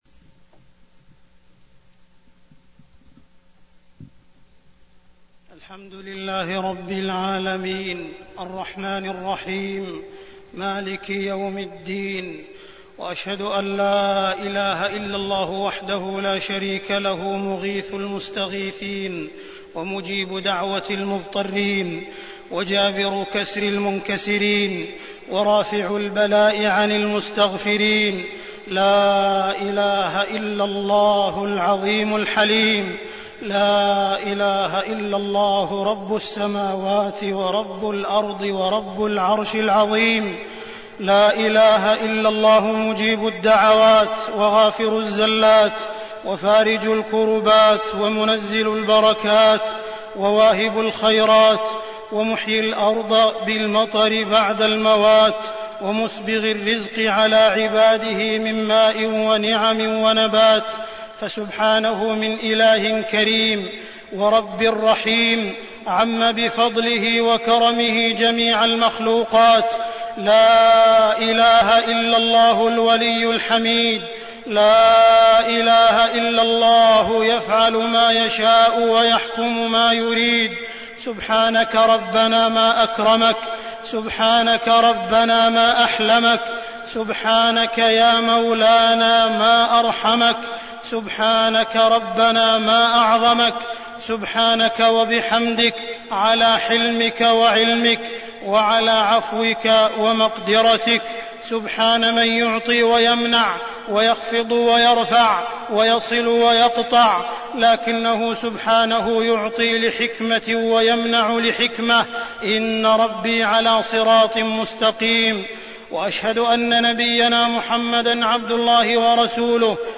تاريخ النشر ٢٠ رجب ١٤١٩ هـ المكان: المسجد الحرام الشيخ: معالي الشيخ أ.د. عبدالرحمن بن عبدالعزيز السديس معالي الشيخ أ.د. عبدالرحمن بن عبدالعزيز السديس نعمة الماء والمطر